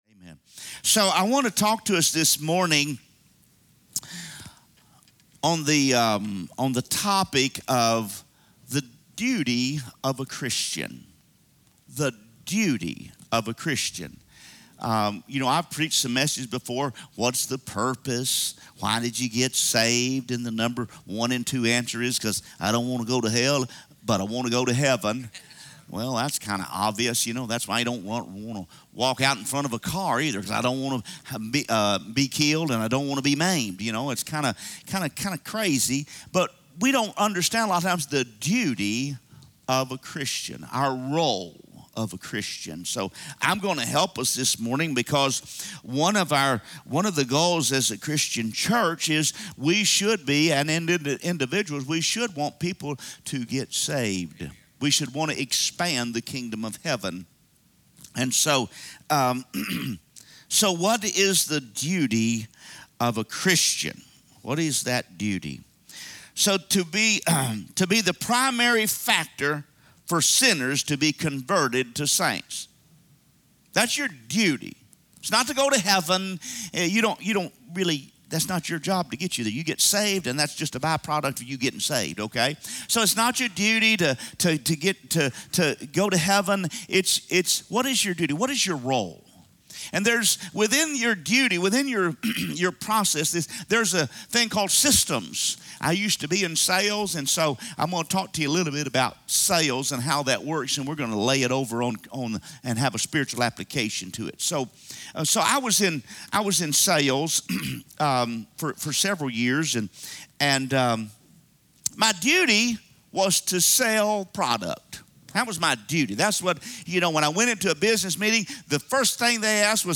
From Series: "Sunday Message"